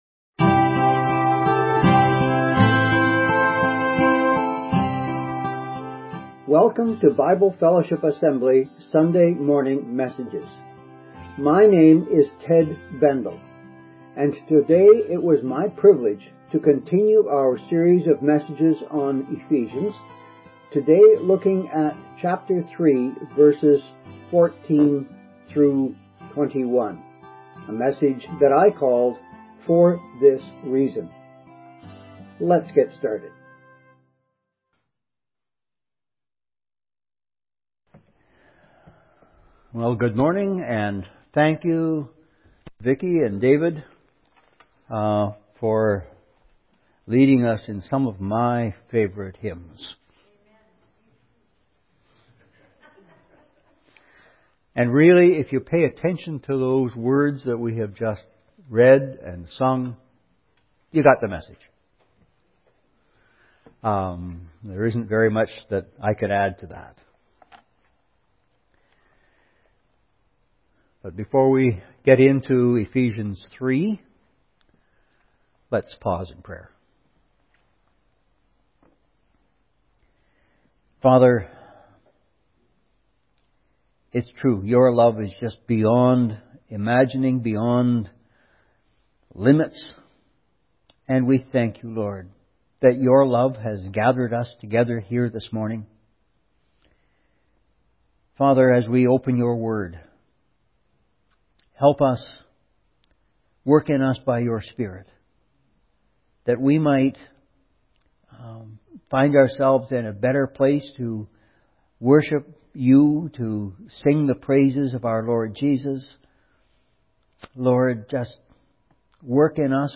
Sunday morning messages from Bible Fellowship Assembly, Porcupine, Ontario (part of the City of Timmins).